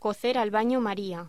Locución: Cocer al baño maría